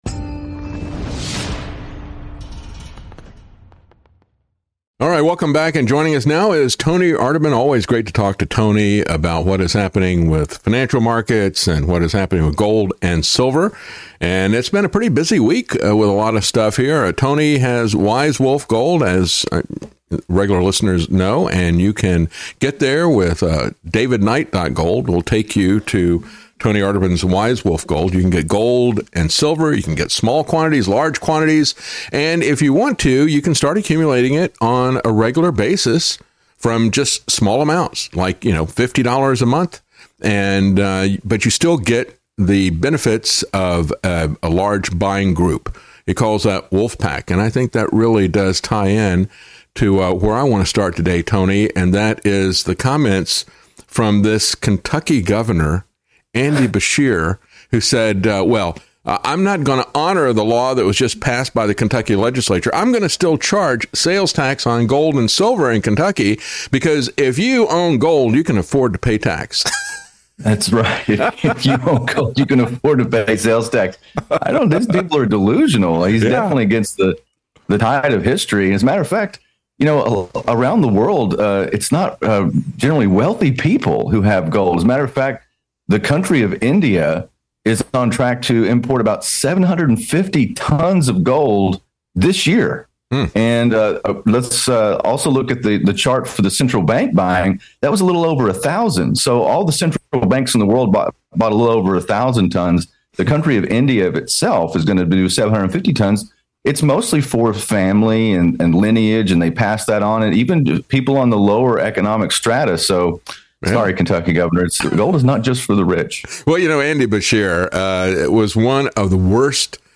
interview-gold-silver-in-shaky-markets-ky-gov-defies-legislature-to-tax-gold.mp3